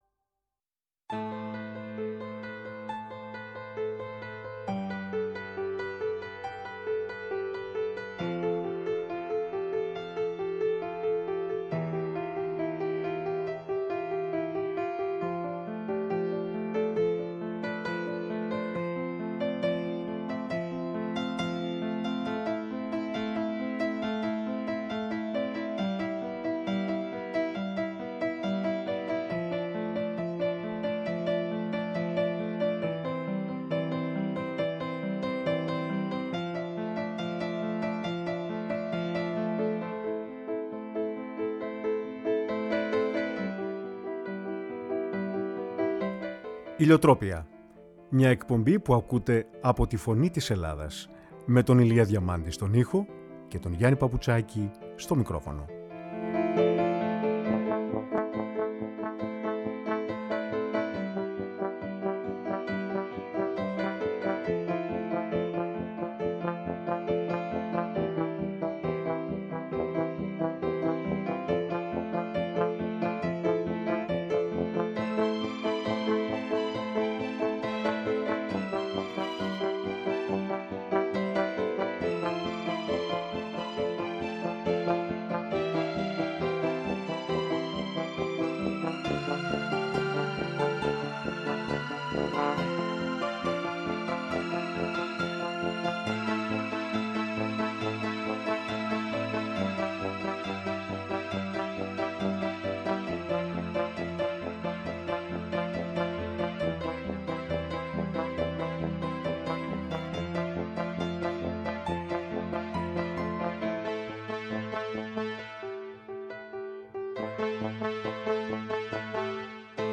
Με ποιήματα του Ρίτσου, του Καρυωτάκη, του Μπρεχτ, του Μπόρχες, του Λειβαδίτη και του Λεοντάρη